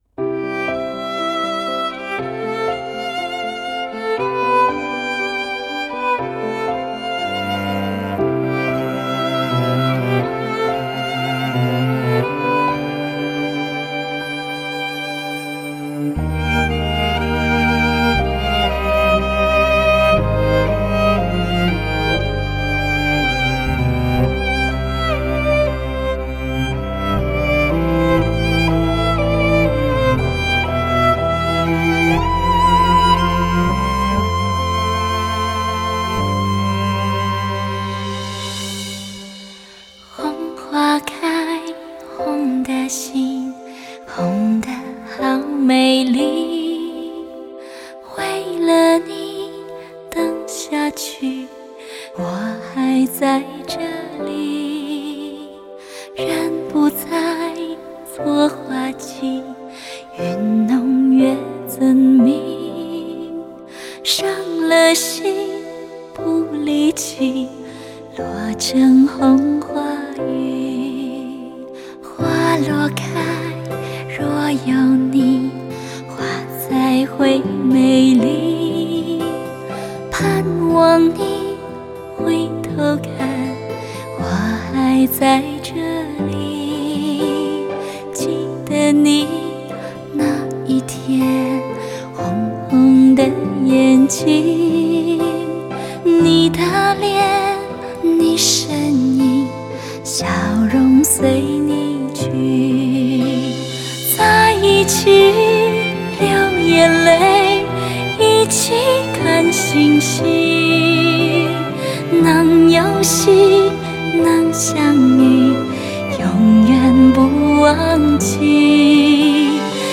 情深款款的演唱令人如痴如醉
编曲气势磅礴，弦乐的演奏尤其突出